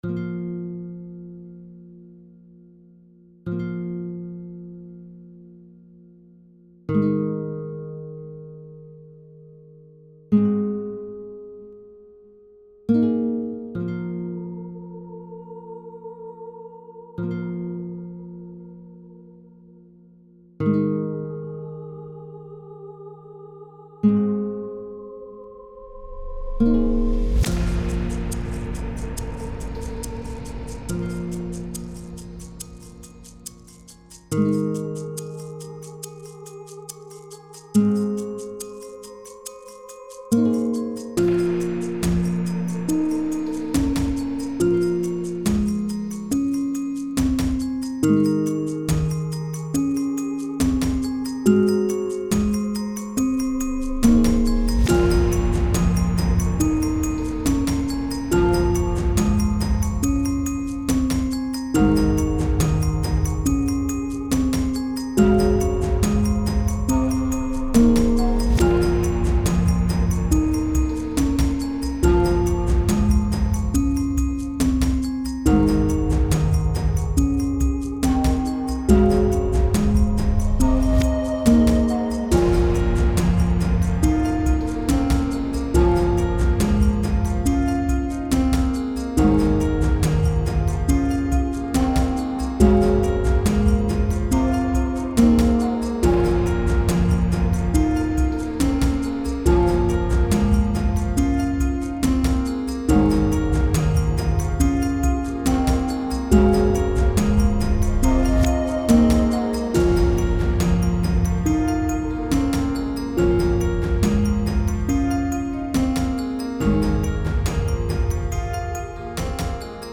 Some Fantasy Music for You